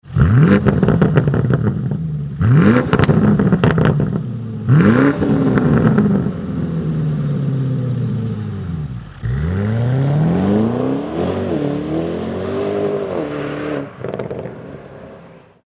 BMW_M840i_REMUS_Racing_Rohr_ESD.mp3